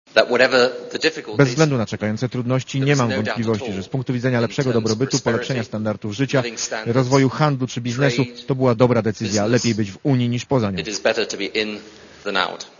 Mam nadzieję, że Polacy wezmą udział w referendum i będą głosować na "tak". Z doświadczeń brytyjskich wynika, że lepiej być wewnątrz Unii Europejskiej niż poza nią - powiedział premier Wielkiej Brytanii